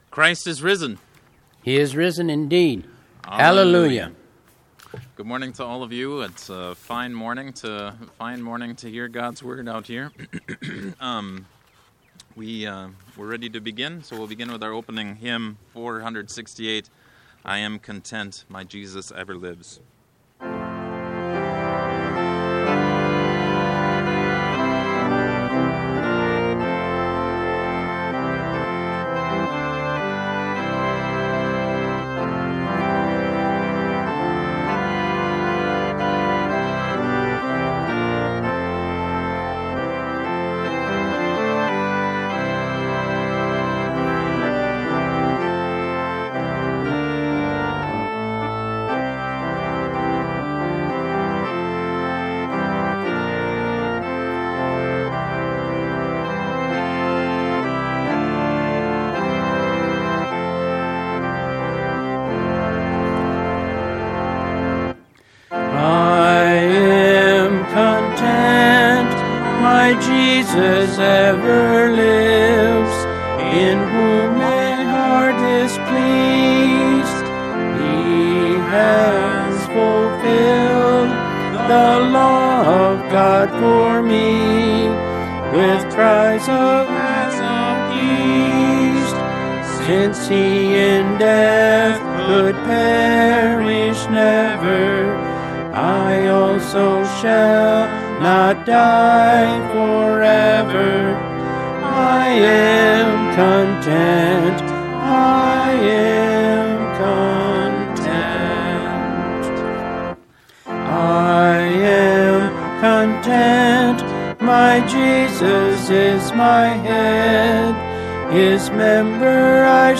200426 Easter 3 Drive in Service